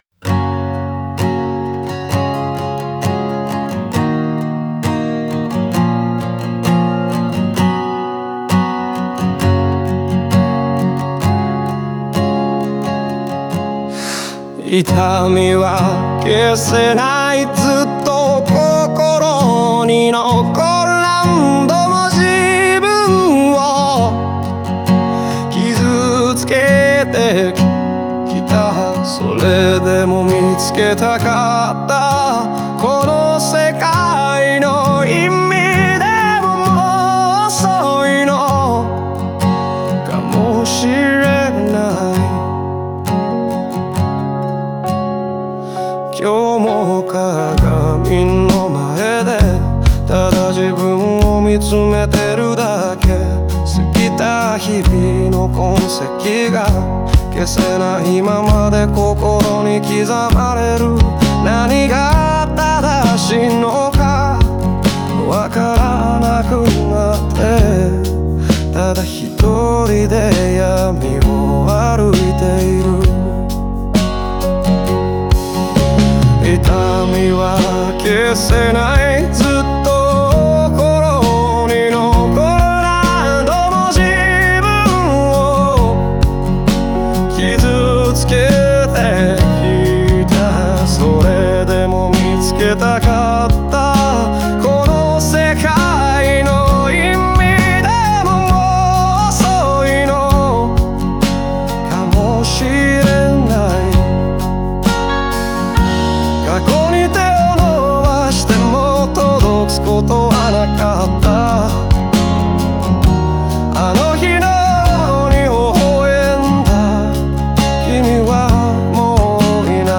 オリジナル曲♪
曲調に合わせて、抑えたテンポとアコースティックな音色が感情の深さを引き立てています。